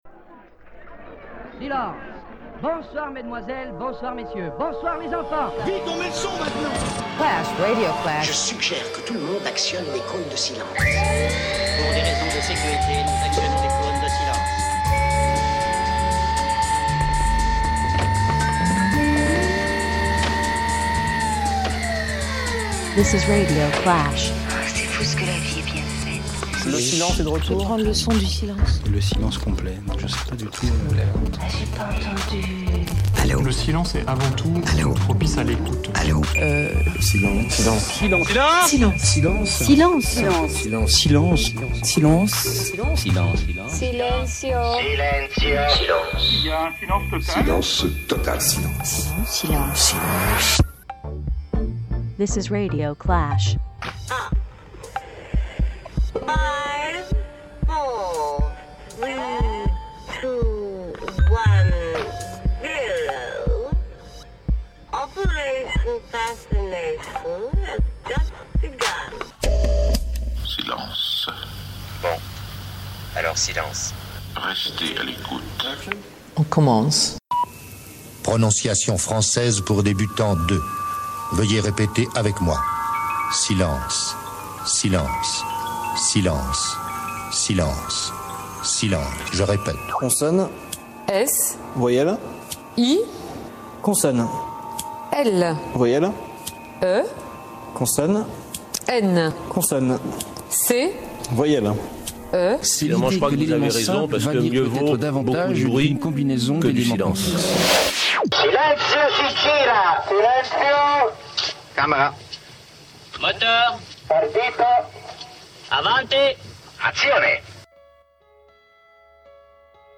Projet d'émissions de radio collaboratives.